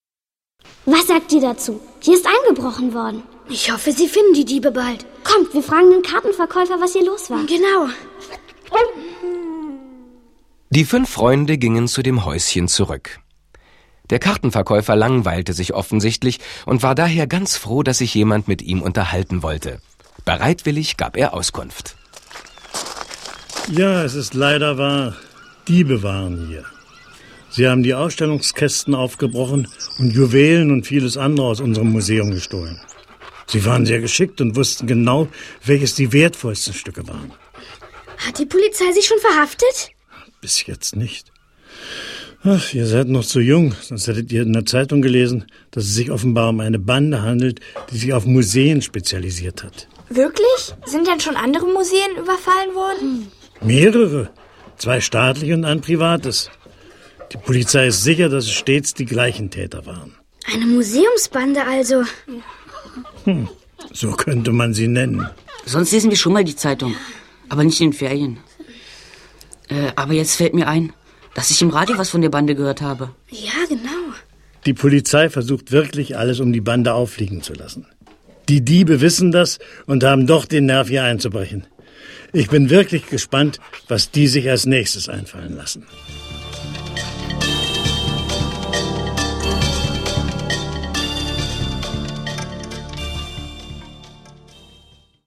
Fünf Freunde - Folge 22: und die Museumsbande | tiptoi® Hörbücher | Ravensburger